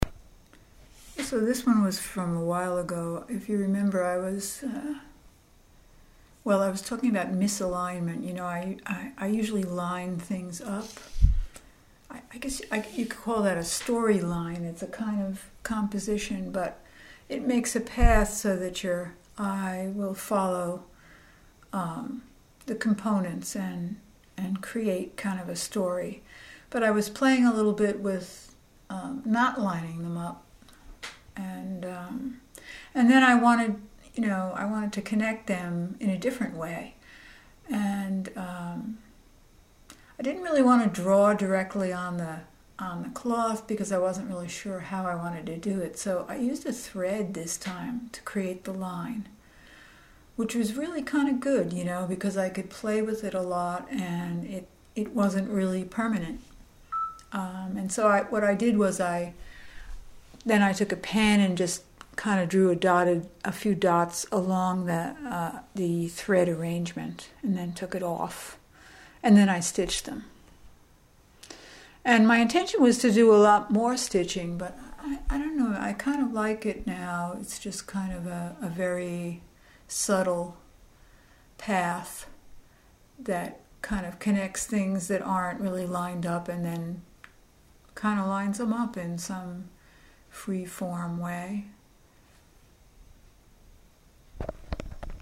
Excuse the ding...